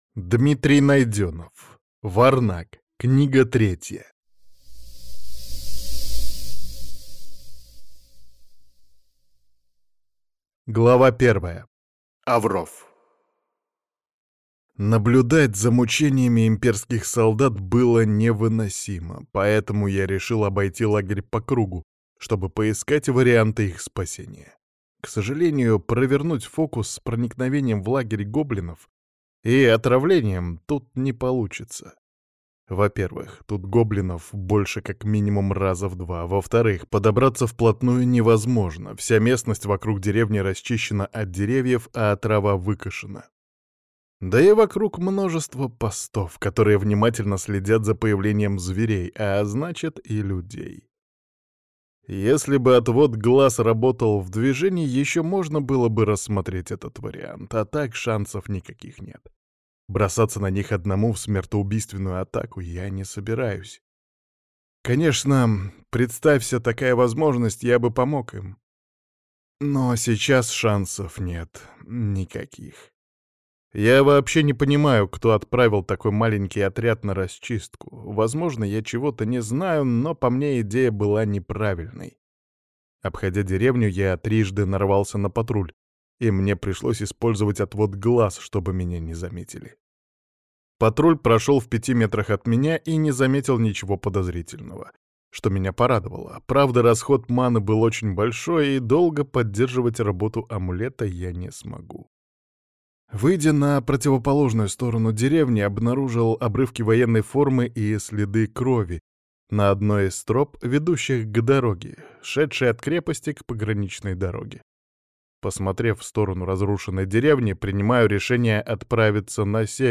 Варнак. Книга третья (слушать аудиокнигу бесплатно) - автор Дмитрий Александрович Найденов